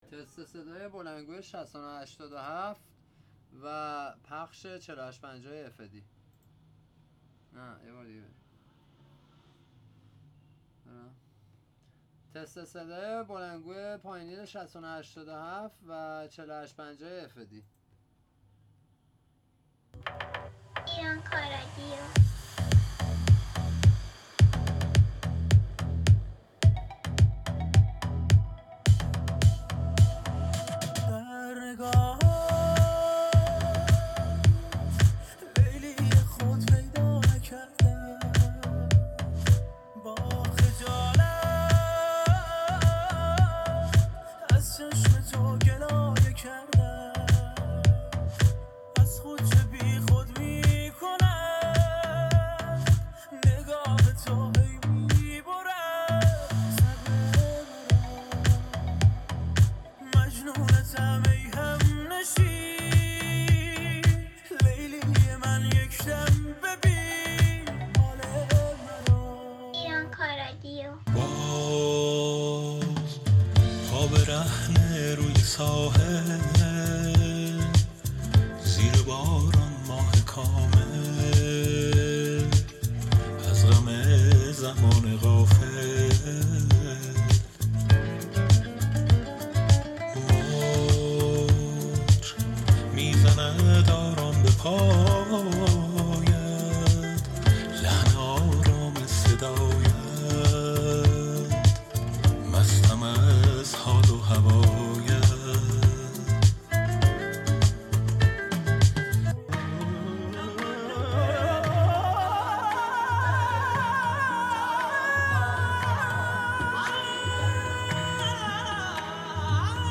تست صدای بلندگوی پایونیر